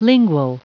Prononciation du mot lingual en anglais (fichier audio)
Prononciation du mot : lingual